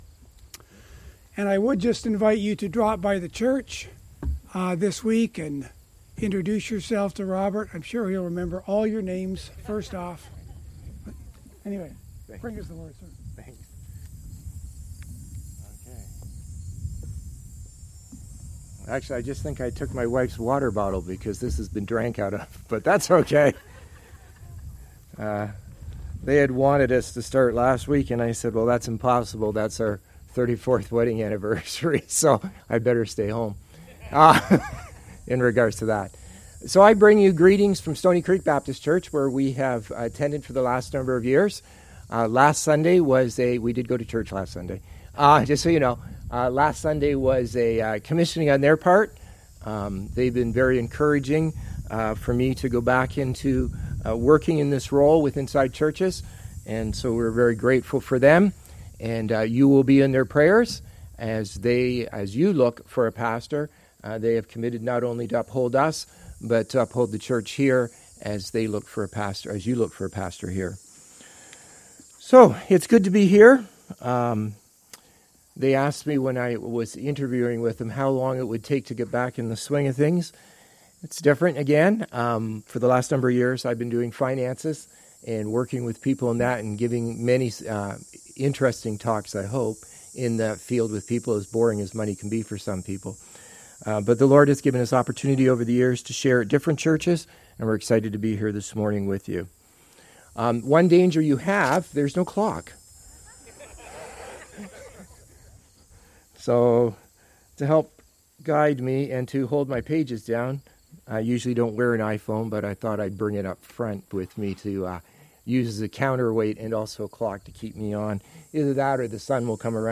Sermons | Forest Baptist Church